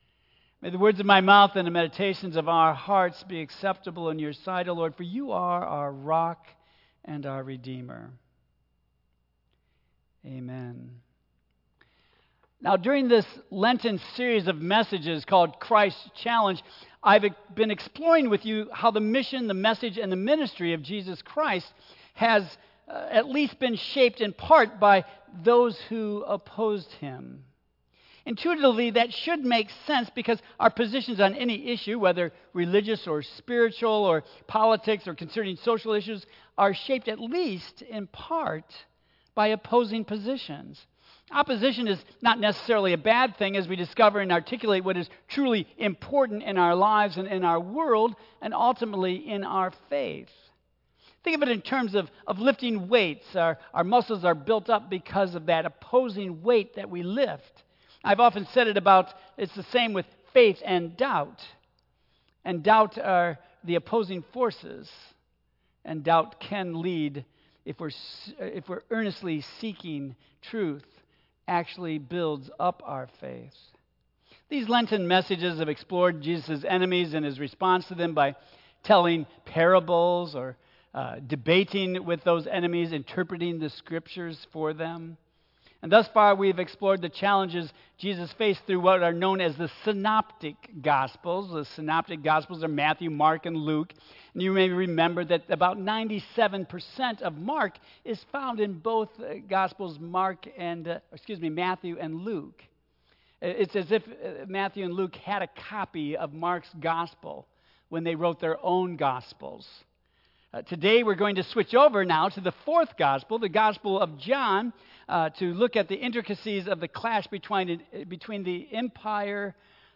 Tagged with Michigan , Sermon , Waterford Central United Methodist Church , Worship Audio (MP3) 8 MB Previous The Very Sad Sadducees